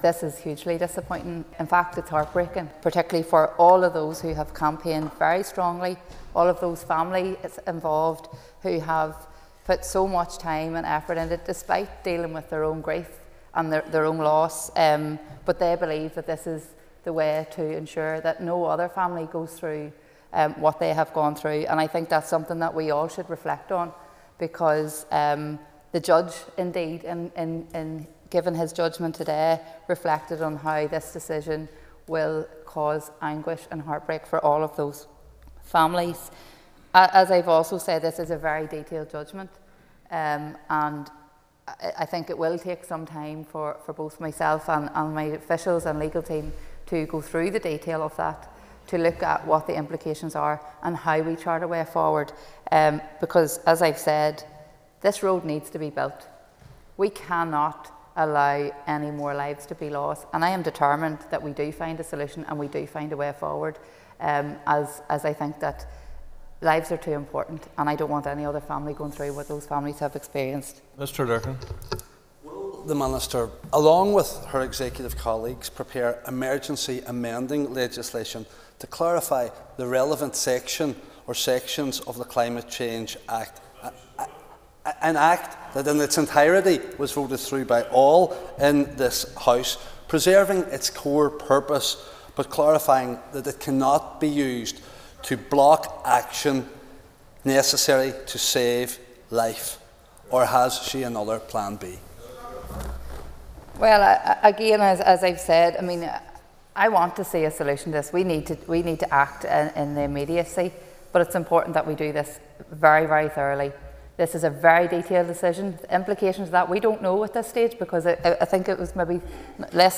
A short time ago, Ms Kimmins addressed the issue in the Assembly, taking questions from Foyle MLA and SDLP Infrastructure Spokesperson Mark Durkan………….